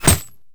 bullet_impact_glass_08.wav